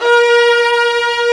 STR VIOLAS04.wav